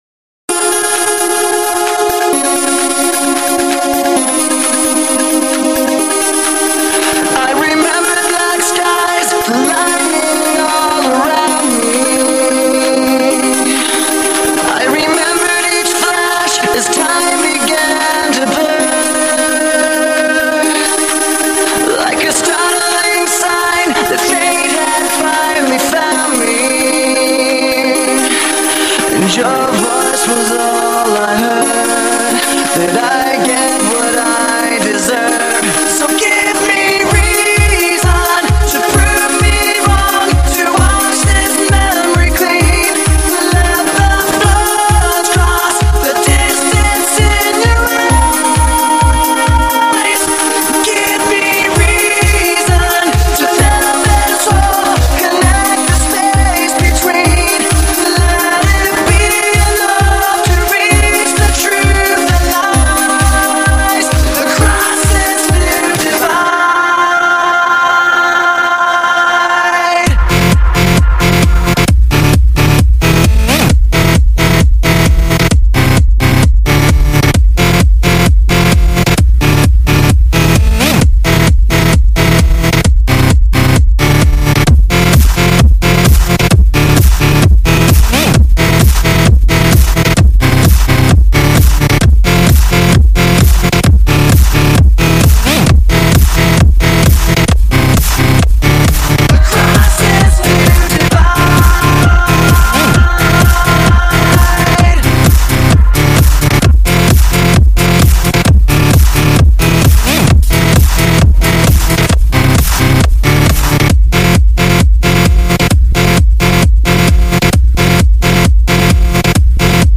Стиль: Electro House